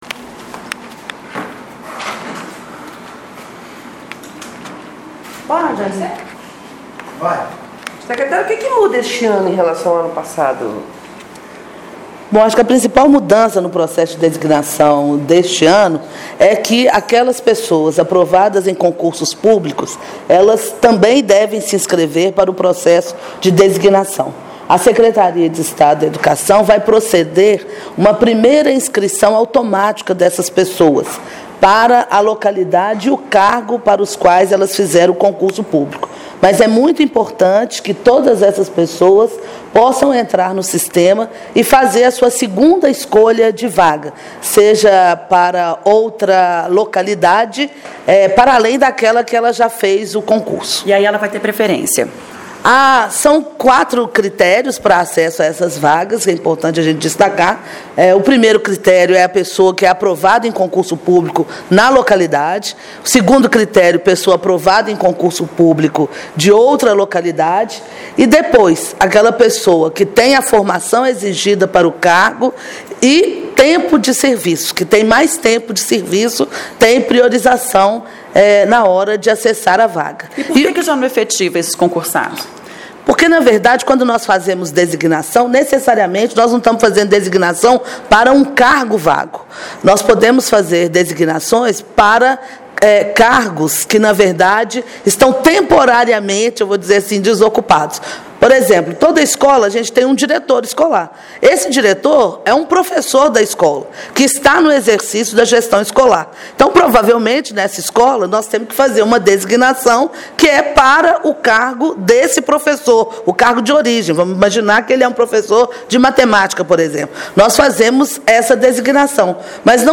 Nesta segunda-feira, o processo foi apresentado, em entrevista coletiva, pela secretária de Estado de Educação, Macaé Evaristo, e pelo subscretário de Gestão de Recursos Humanos da SEE, Antônio David de Souza.
AQUI o áudio da entrevista da secretária Macaé Evaristo.